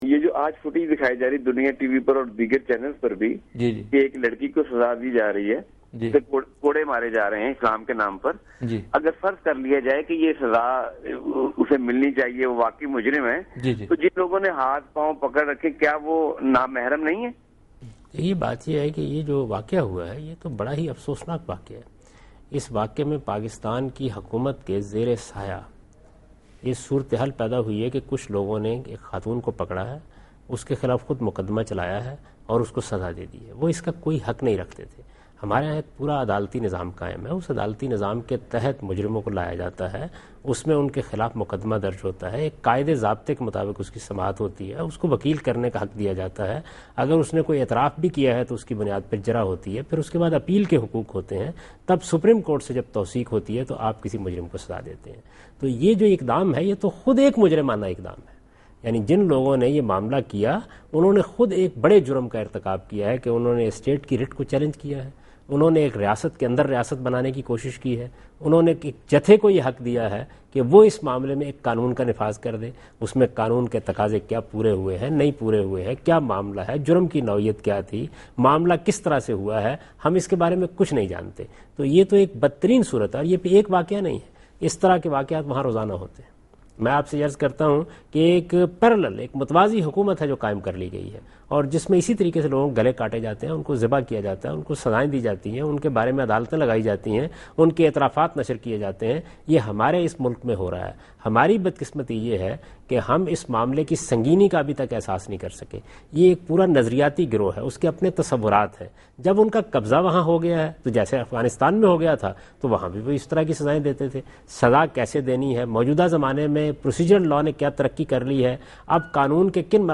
Category: TV Programs / Dunya News / Deen-o-Daanish /
Answer to a Question by Javed Ahmad Ghamidi during a talk show "Deen o Danish" on Dunya News TV